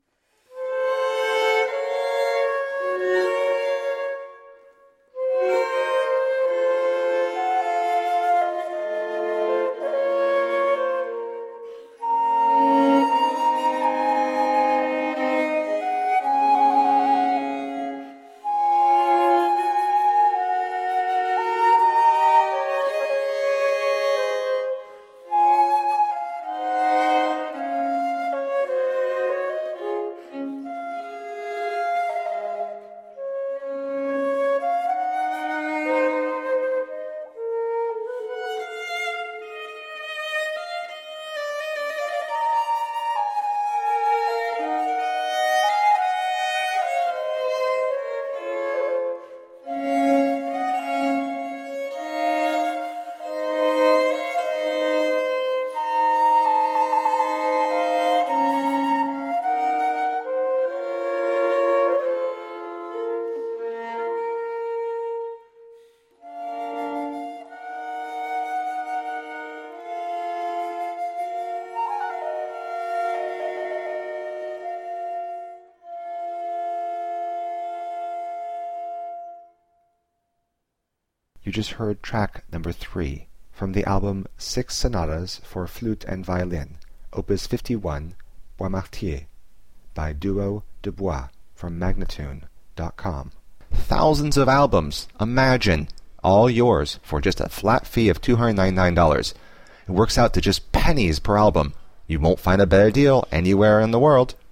Exquisite chamber music.